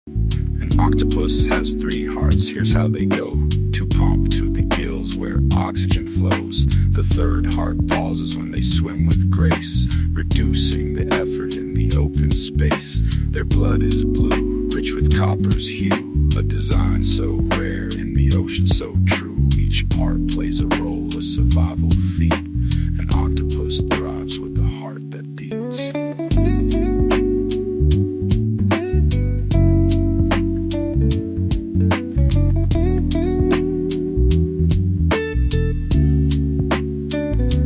LoFi educational video